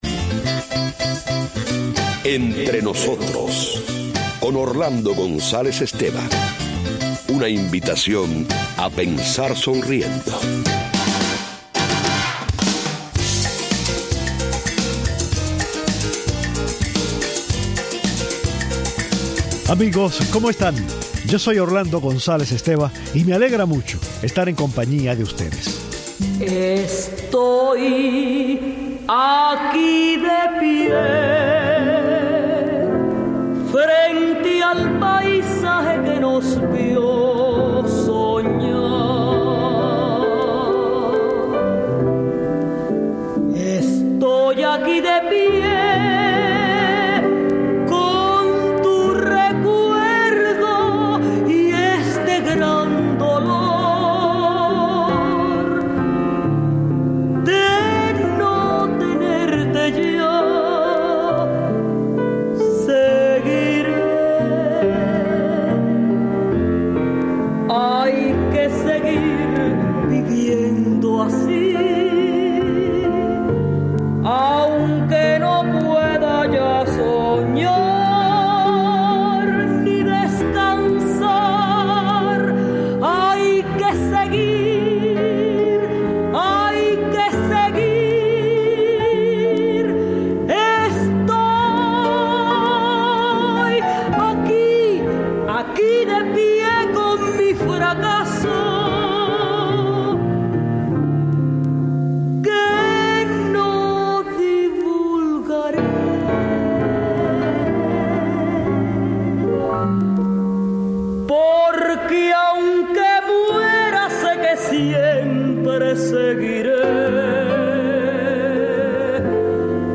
Hoy recordamos a Heberto Padilla, leemos y comentamos algunos de sus poemas y escuchamos algunas de las canciones más populares en la Cuba de los años sesenta.